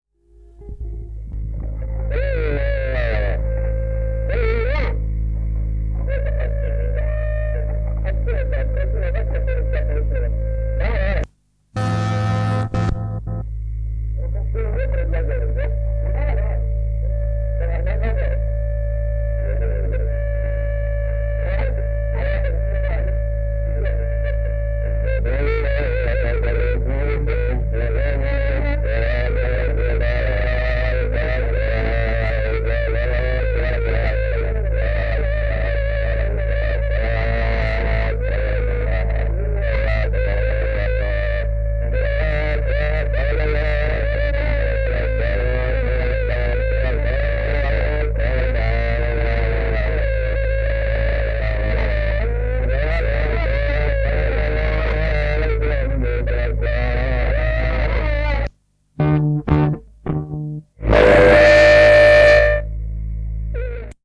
Speceffect.mp3